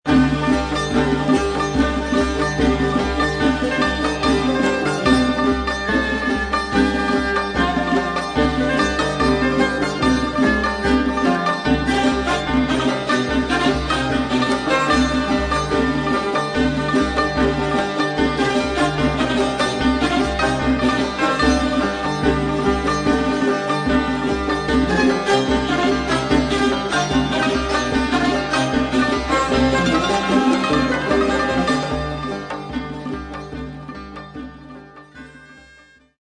پایۀ قسمت سوم آهنگ به صورت زیر است.